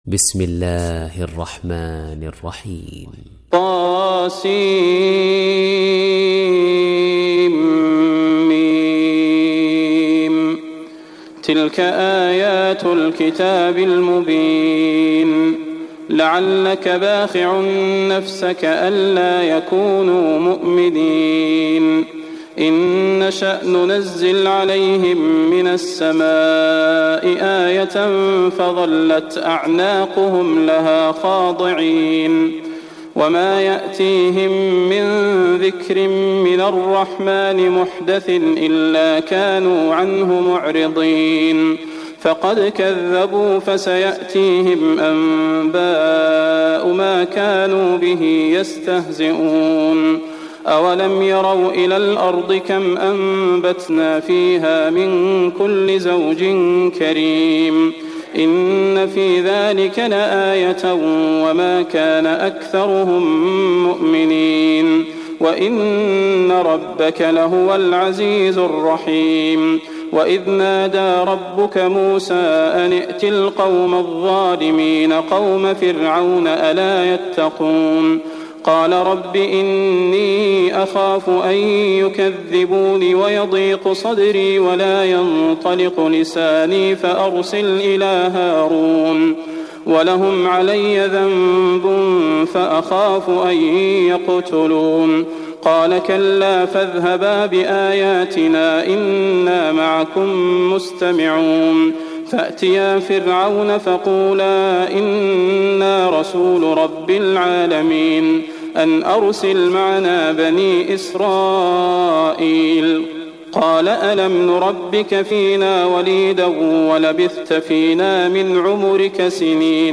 تحميل سورة الشعراء mp3 بصوت صلاح البدير برواية حفص عن عاصم, تحميل استماع القرآن الكريم على الجوال mp3 كاملا بروابط مباشرة وسريعة